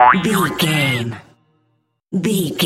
Short musical SFX for videos and games.,
Epic / Action
Fast paced
In-crescendo
Ionian/Major
aggressive
bright
energetic
funky